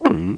gulp.mp3